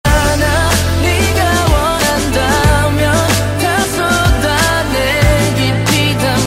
Download Concert sound effect for free.
Concert
Music Concert